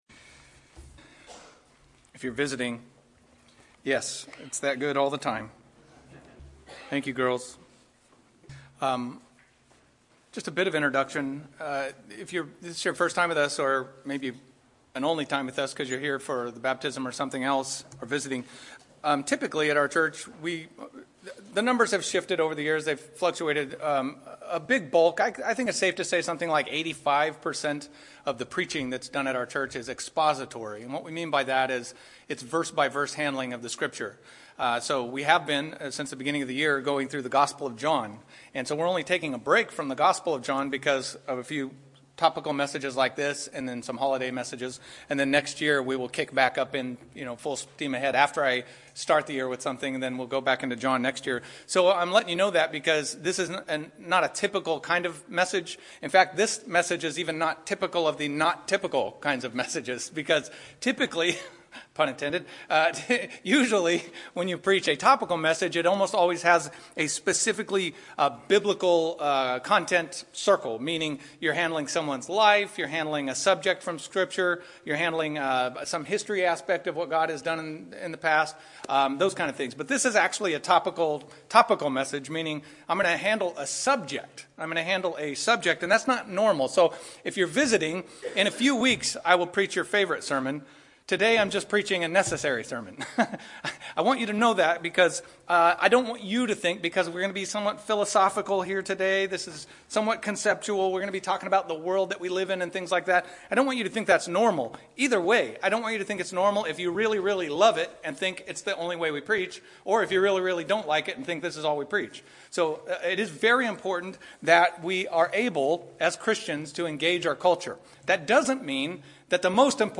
A message from the series "The Gospel of John."